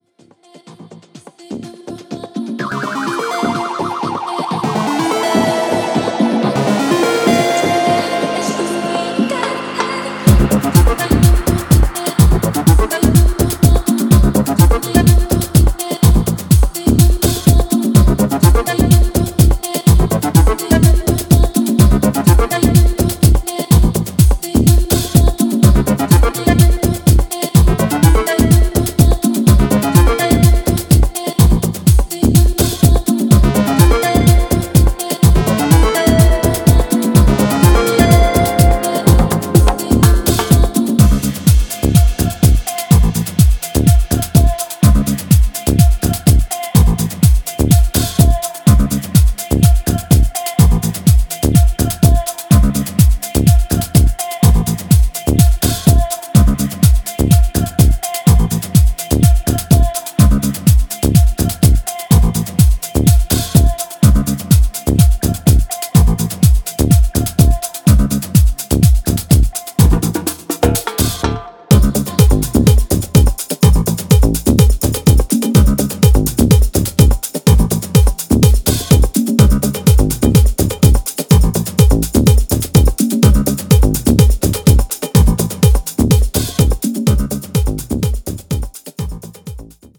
ラテン/アフロのヴァイブで低空飛行する